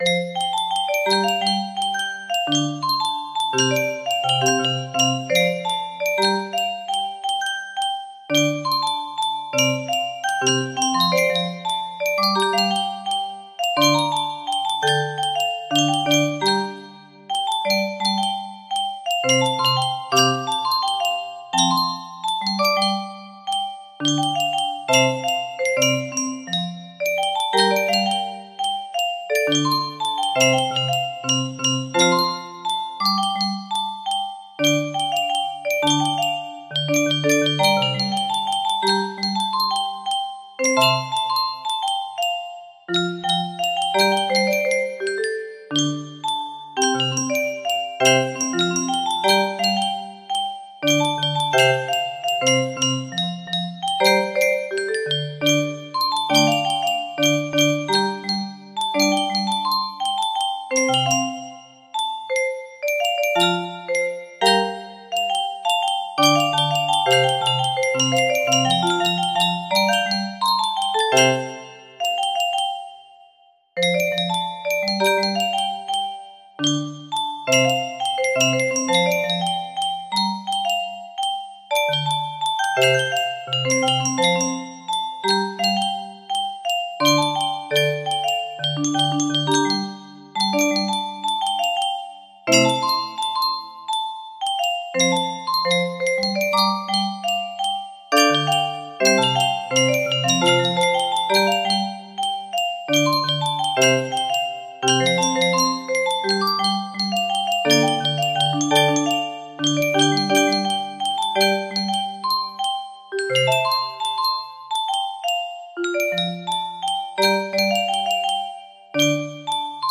Full range 60
R&B meets Music Box! <3